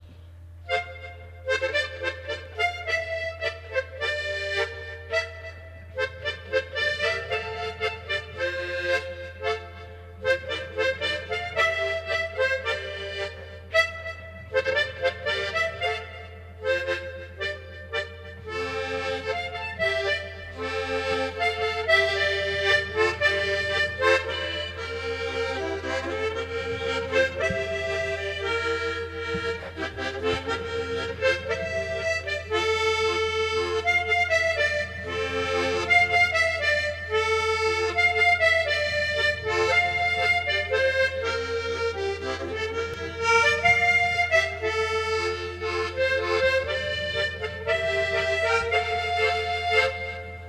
Tremolo